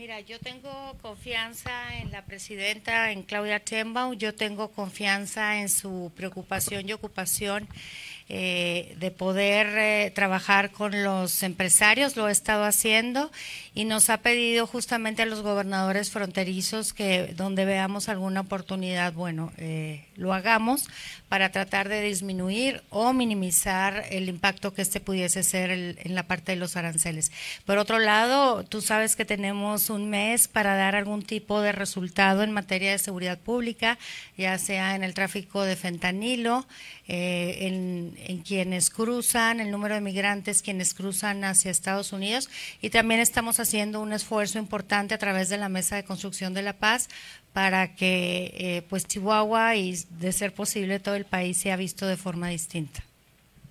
Durante una rueda de prensa llevada a cabo este martes 11, fue interrogada sobre la estrategia del gabinete federal en el ámbito económico, sobre si existe un plan de la misma magnitud que el implementado para brindar apoyo humanitario a los migrantes connacionales, centro y sudamericano que deporte Estados Unidos.